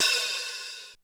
HiHatOp.wav